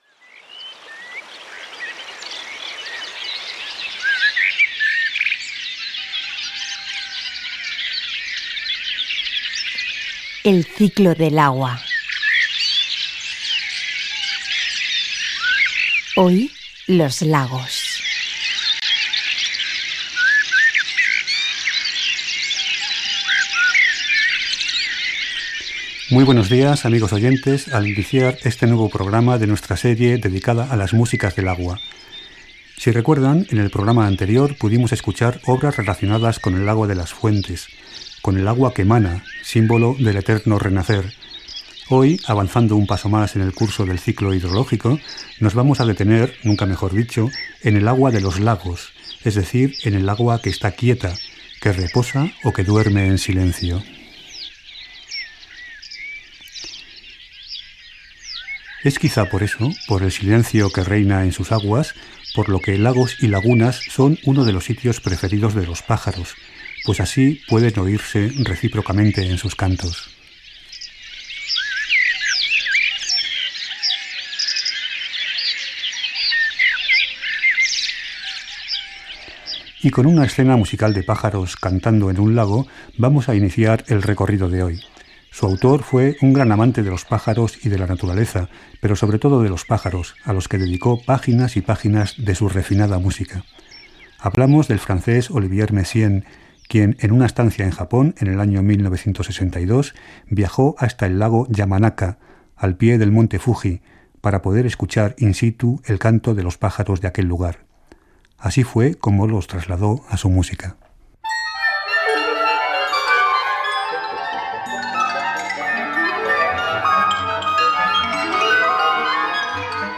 Careta del programa, espai dedicat a la música relacionada amb els llacs. Benvinguda, record del programa anterior, comentari sobre els llacs i tema musical
Musical